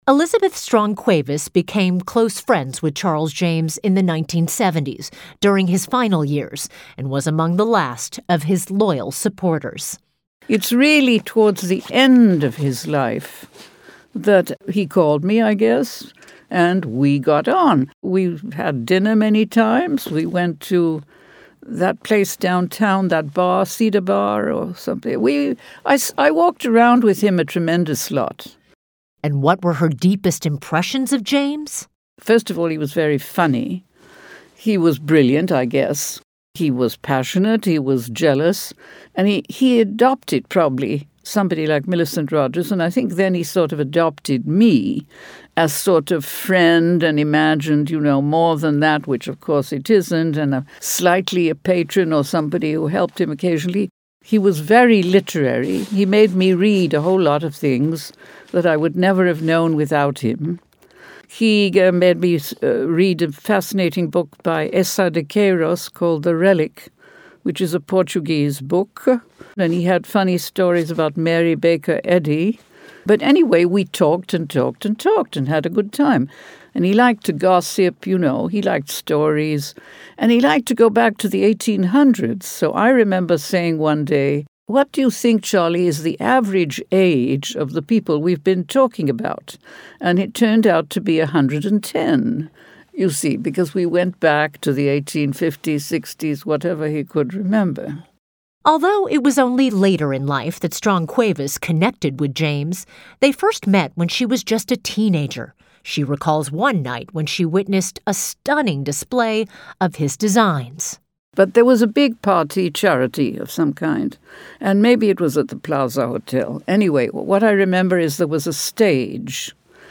Recalling Charles James: Audio Interviews
The interviews are chronologically ordered according to the date each subject knew Charles James.